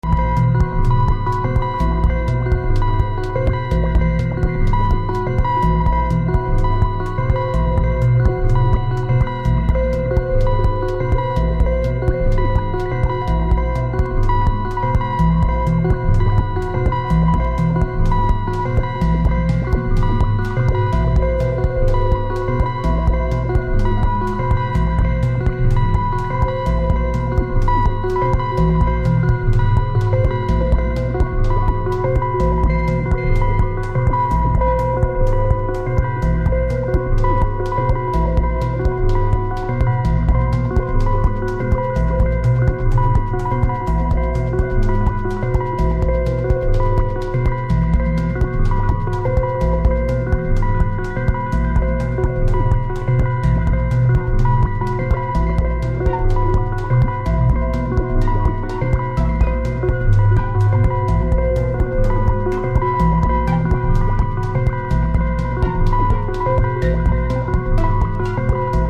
TECHNO/ELECTRO